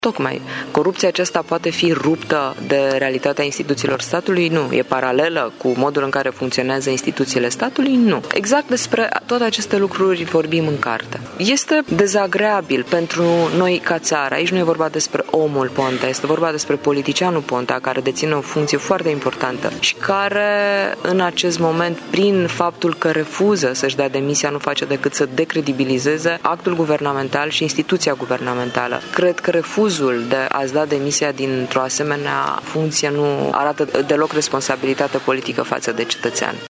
Într-un interviu acordat în exclusivitate pentru Radio Tîrgu-Mureș, Săftoiu spune că mecanismele de corupție de la nivel înalt sunt descrise în cartea sa.